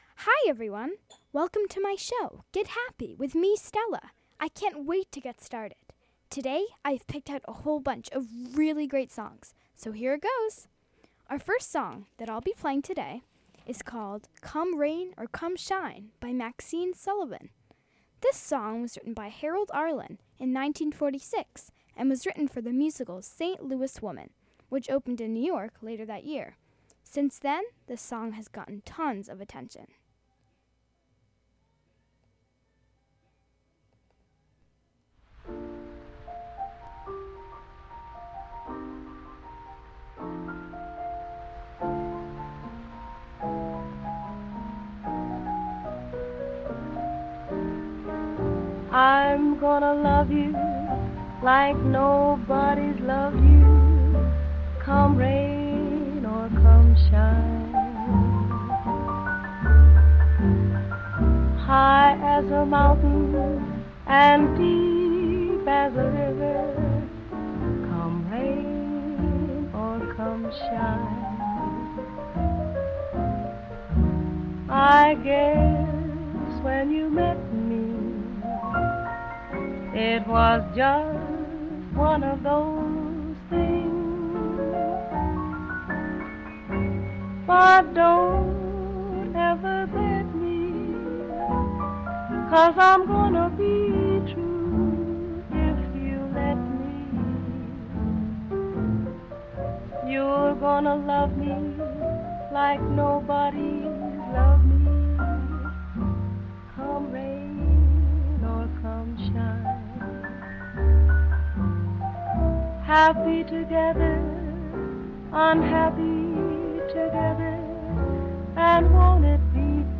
1930s and 1940s music